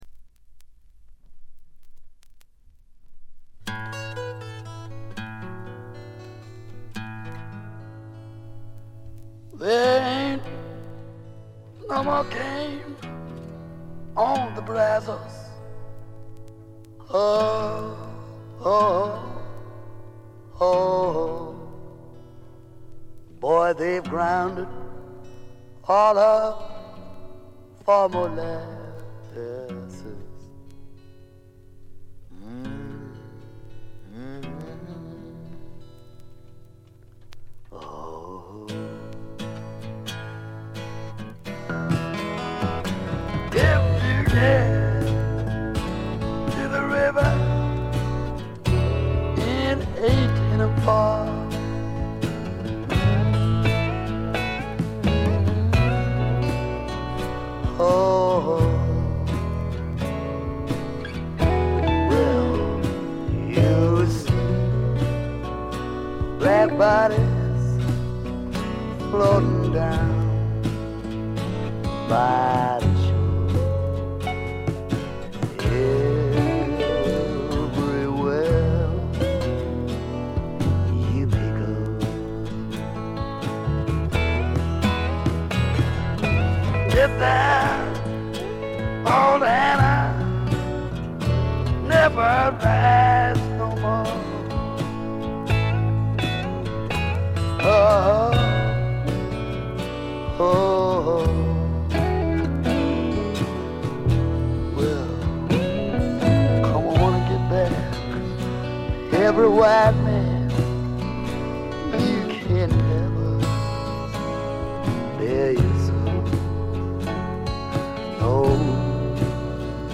ごくわずかなノイズ感のみ。
試聴曲は現品からの取り込み音源です。
Recorded at:Nova Sounds and Island Studios
vocal and guitar